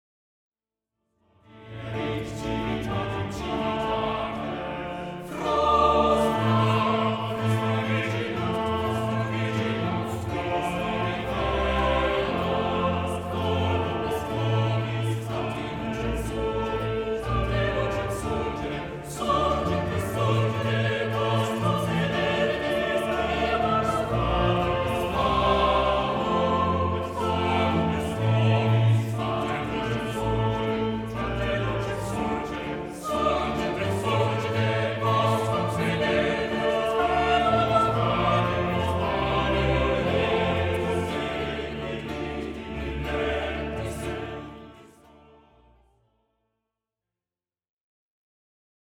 Un manifeste flamboyant de la révolution baroque